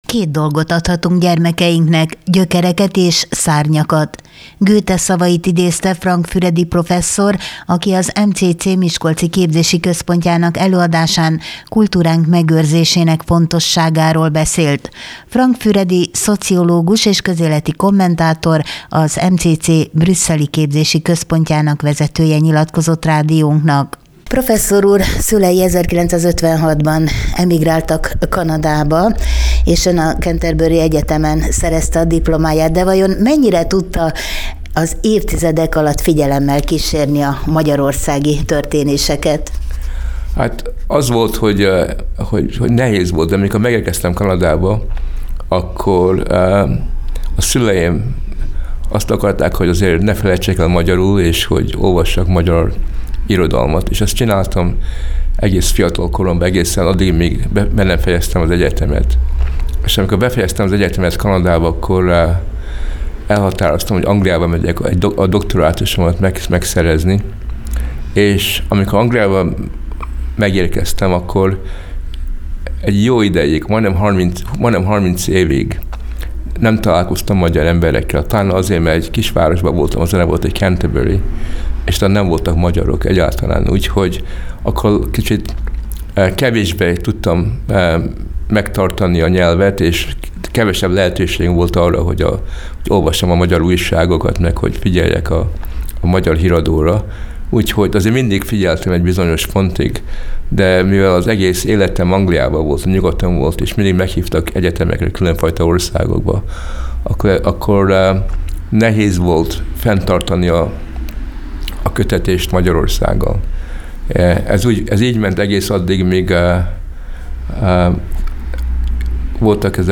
Goethe szavait idézte Frank Füredi professzor, aki az MCC miskolci képzési központjának előadásán kultúránk megőrzésének fontosságáról beszélt. A fiatalok csak akkor tudnak újítóvá válni és szárnyalni, ha erős gyökerekkel és identitástudattal rendelkeznek.